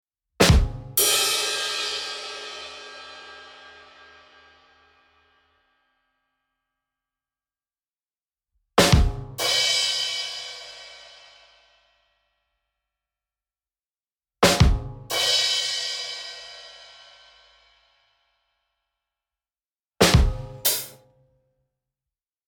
Badum Tss